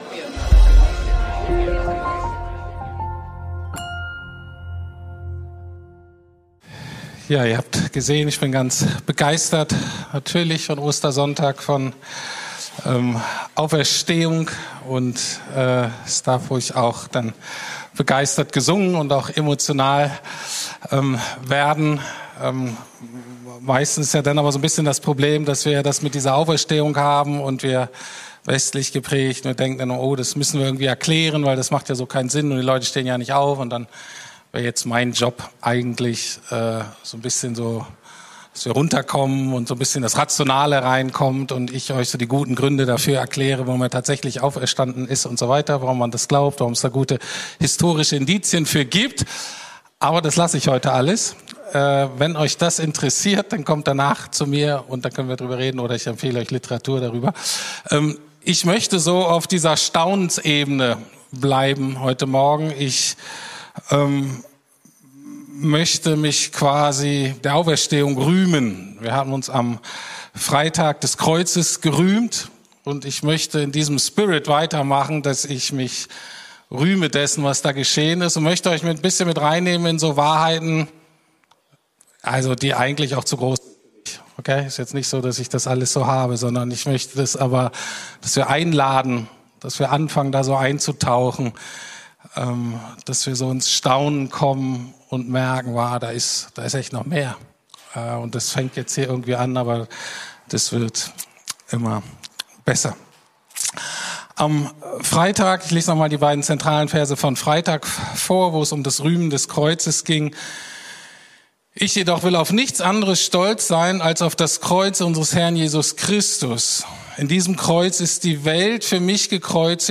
Das Rühmen der Auferstehung (Ostersonntagspredigt) ~ Predigten der LUKAS GEMEINDE Podcast